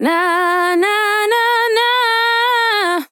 Categories: Vocals Tags: DISCO VIBES, dry, english, female, fill, NA, NAH, sample